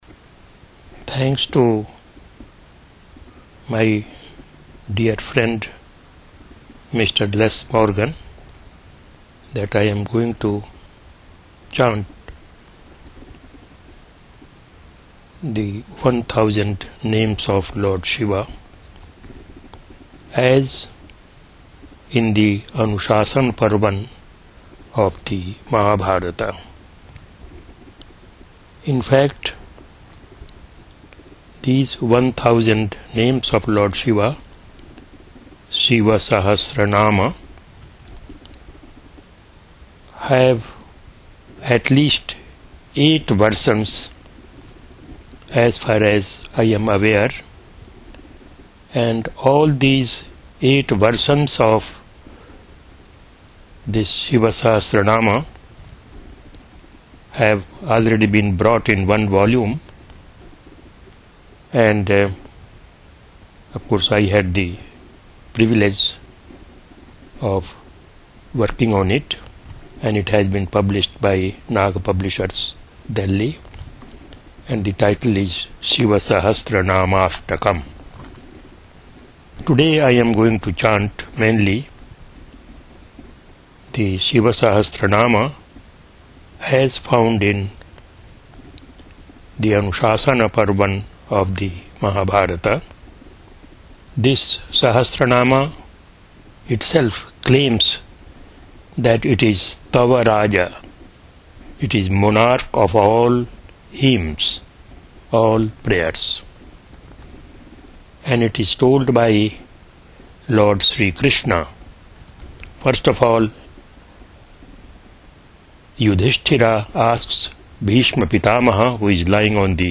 recorded on 11 June 2009 in San Francisco
Śiva Sahasranāma with introduction in English, chanted Sanskrit text with phalaśruti (mp3, 128kbs, 45:50, 43 MB)
chanting audio files